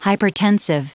Prononciation du mot hypertensive en anglais (fichier audio)
Prononciation du mot : hypertensive